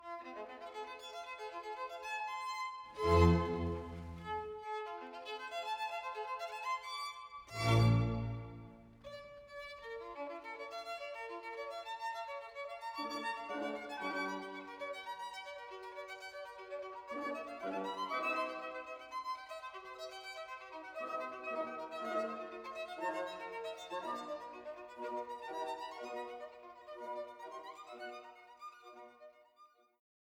Chor der Diener Zedekias